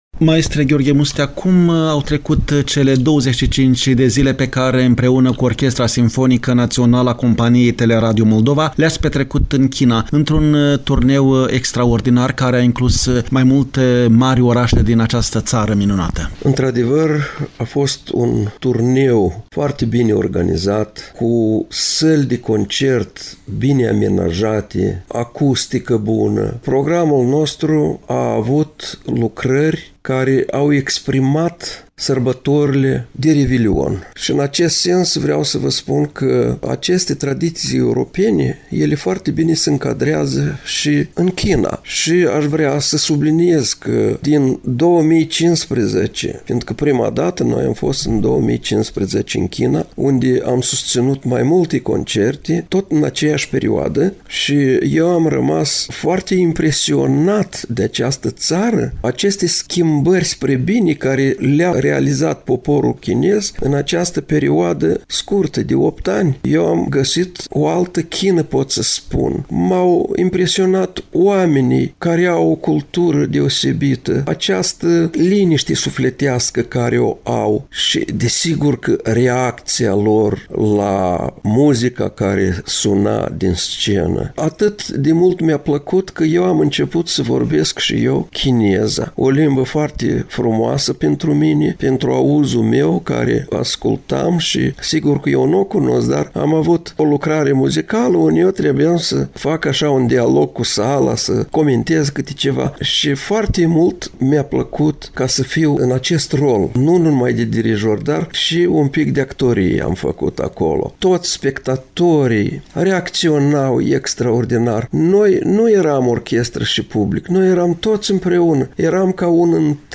a declarat maestrul Gheorghe Mustea în interviul oferit